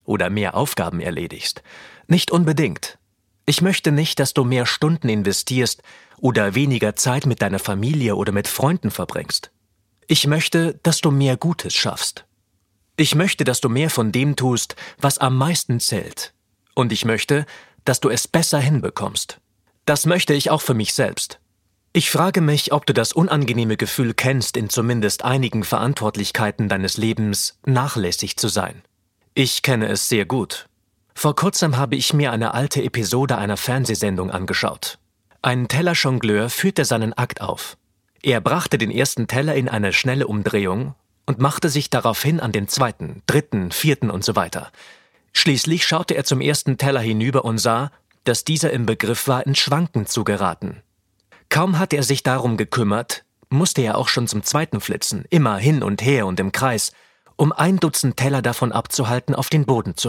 • Sachgebiet: Hörbücher